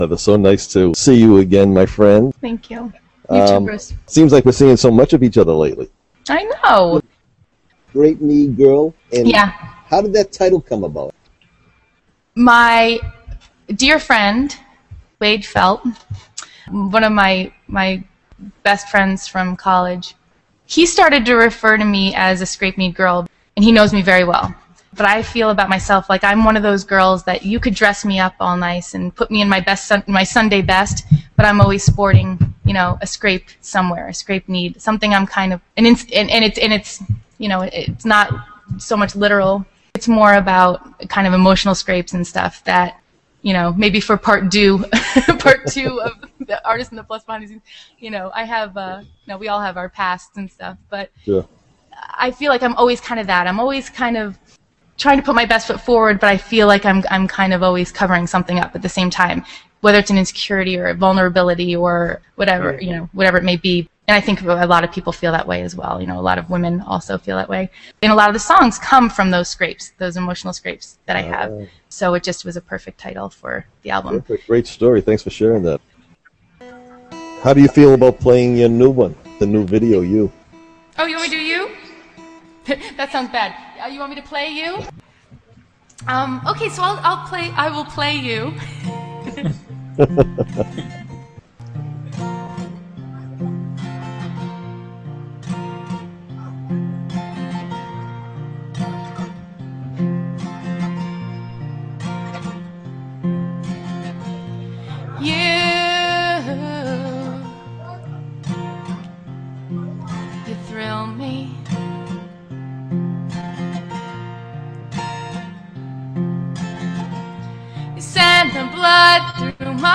Here is a short piece of that interview.